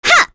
Selene-Vox_Attack6.wav